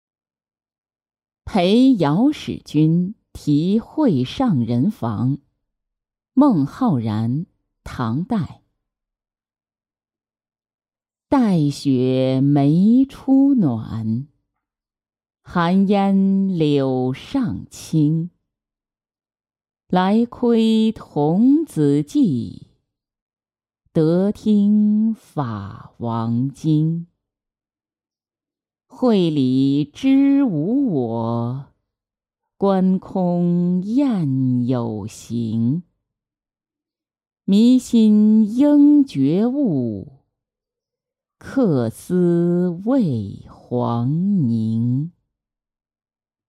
陪姚使君题惠上人房-音频朗读